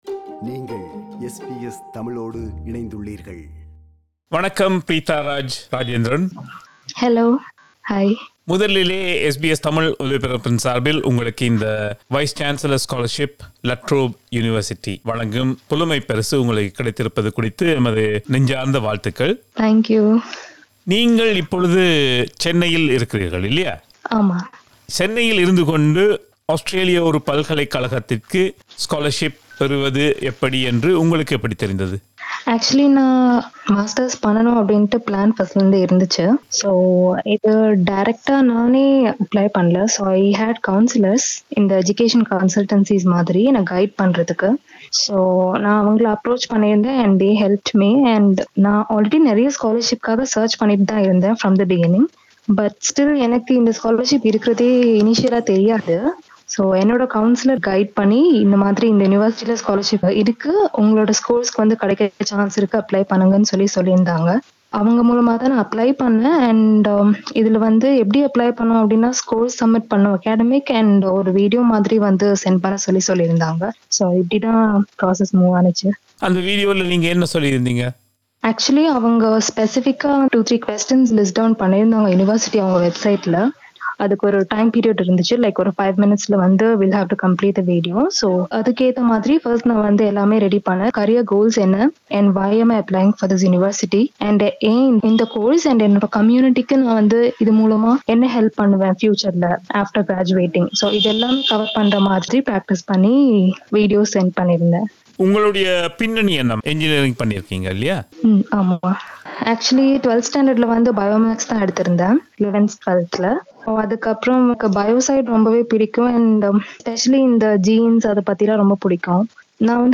Australian news bulletin for Thursday 26 August 2021.